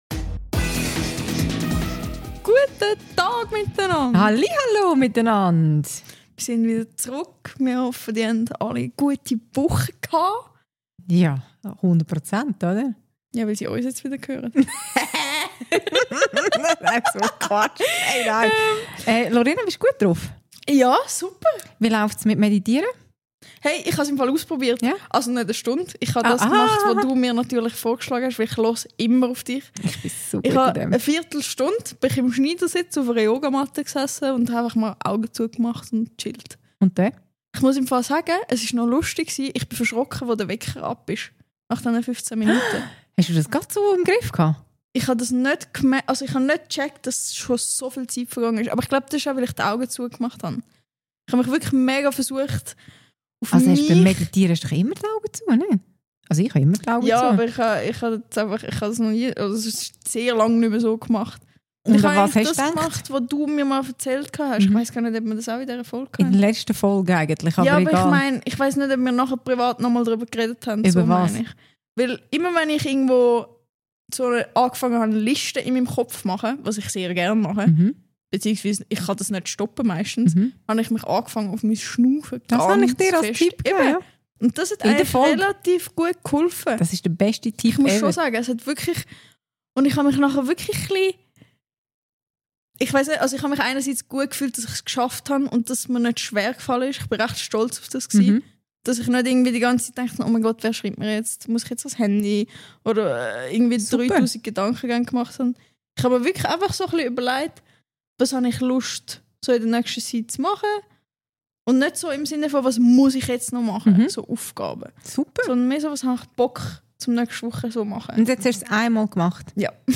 Ein Generationen-Gespräch über ein Kompliment, das sich nicht wie eines anfühlt.